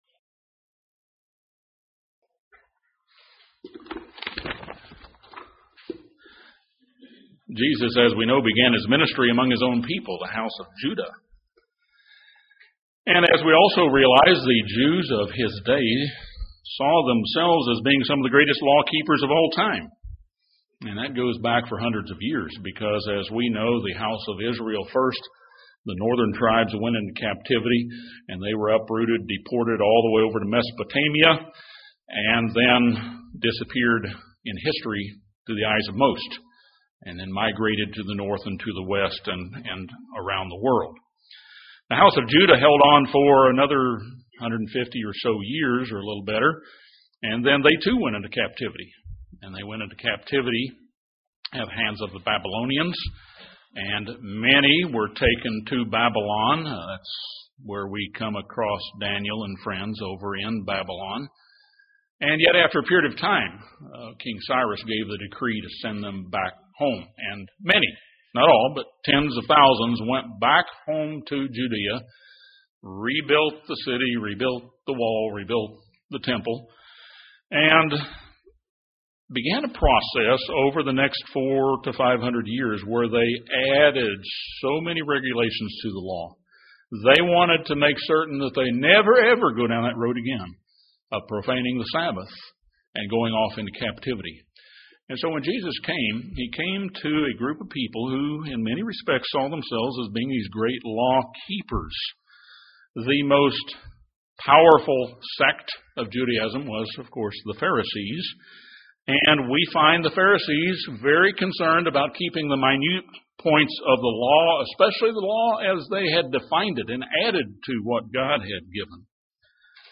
This sermon expounds the parables of the lost sheep, lost coin, lost son and the workers in the vineyard.